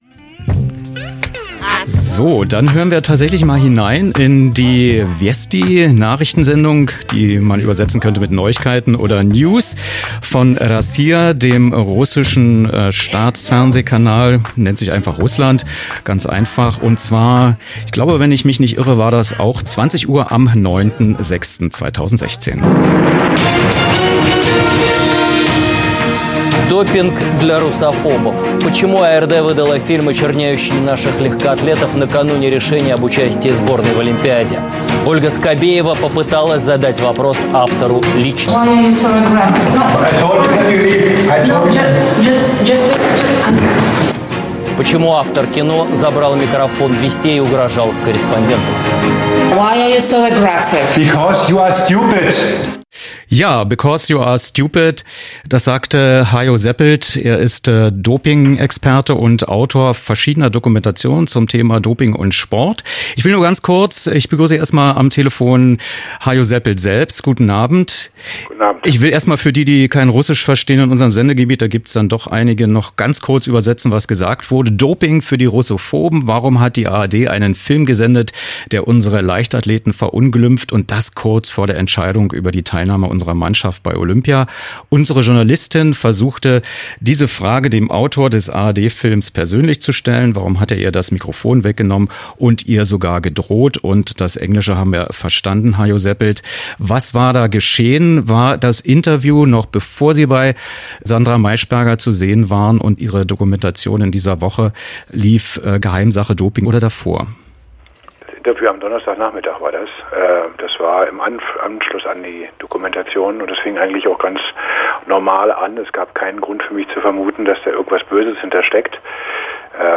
Was: Telefoninterview zu den Umständen des Rossija-Interviews